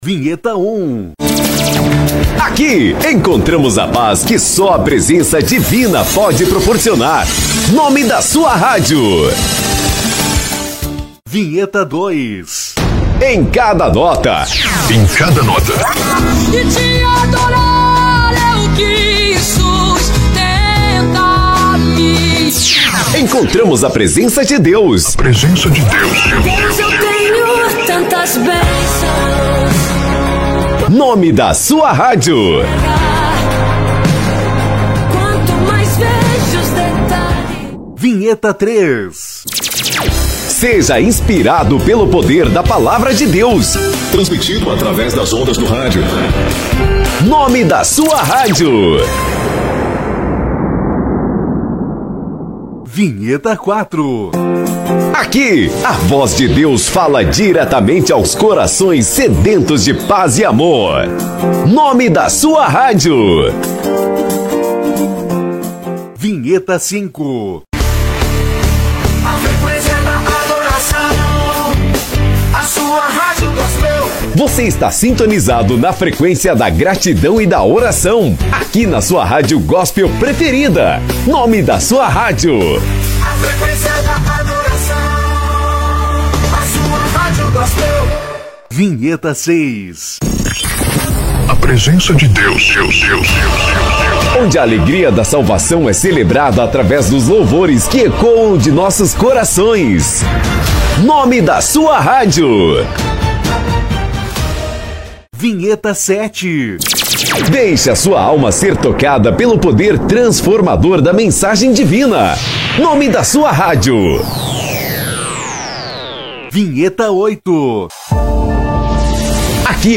– Nome da sua rádio gravado com locução profissional
– Estilo Gospel